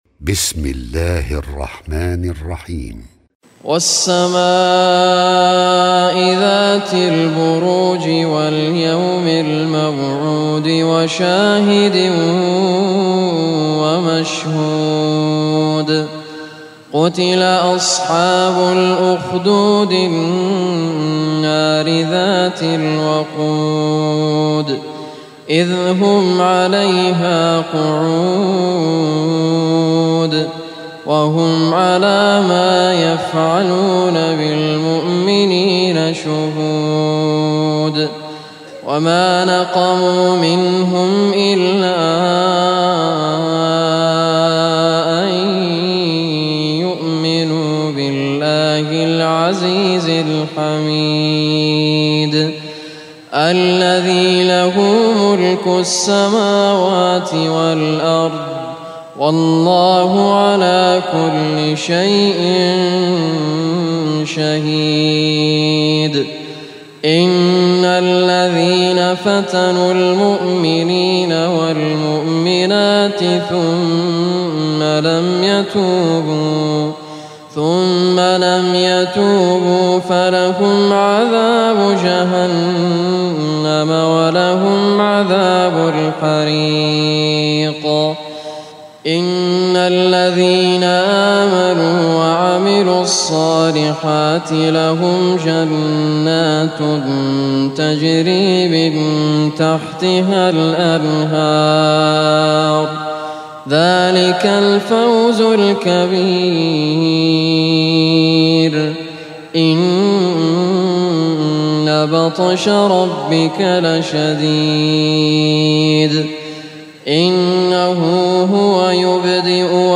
Surah Al-Buruj MP3 Recitation by Raad AL Kurdi
This surah is recited by Sheikh Muhammad Raad Al Kurdi.